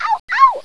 ouch.wav